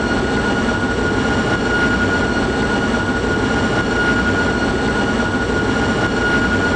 Edit sounds to remove noise and make them loop better